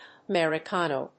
/ʌˌmɛrɪˈkɑno(米国英語), ʌˌmerɪˈkɑ:nəʊ(英国英語)/
フリガナアメリカノー